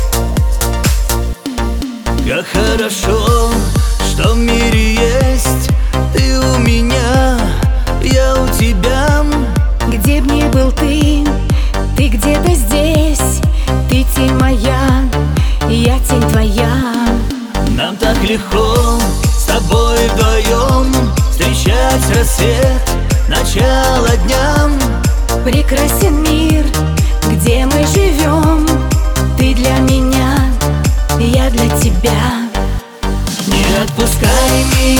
Жанр: Русский поп / Русский рэп / Русский шансон / Русские